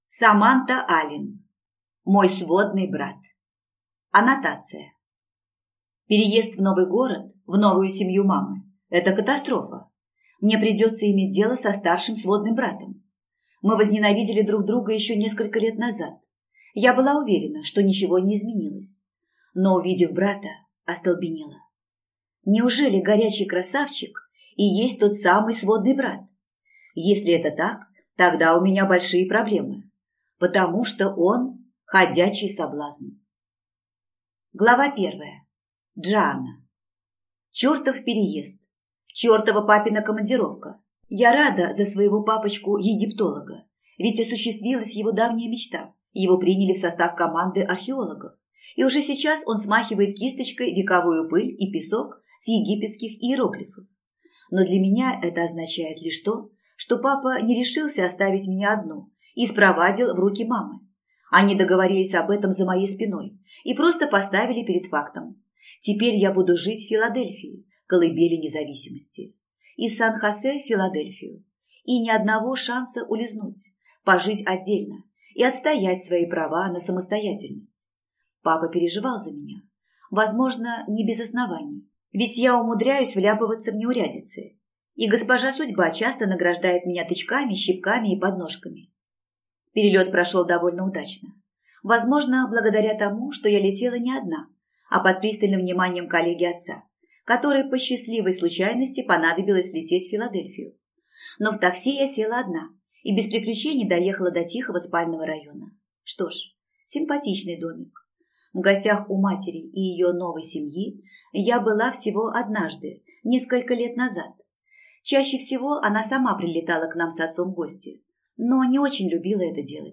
Аудиокнига Мой сводный брат | Библиотека аудиокниг
Прослушать и бесплатно скачать фрагмент аудиокниги